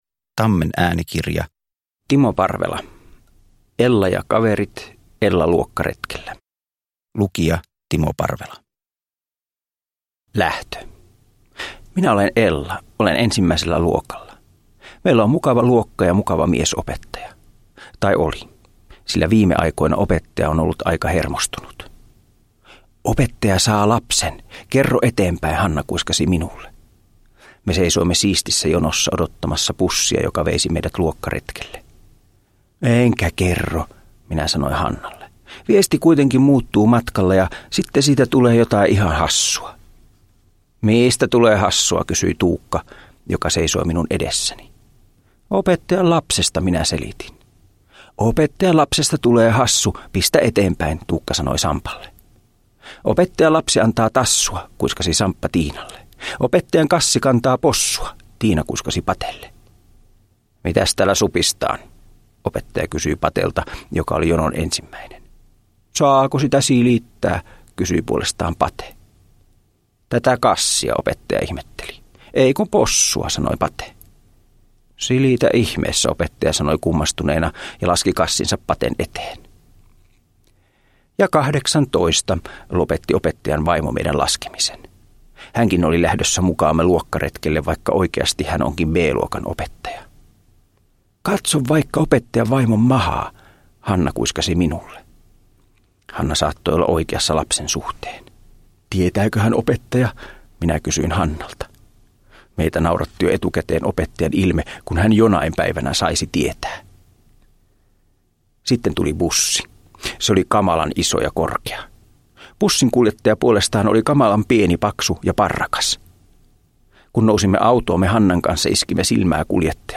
Ella luokkaretkellä – Ljudbok
Uppläsare: Timo Parvela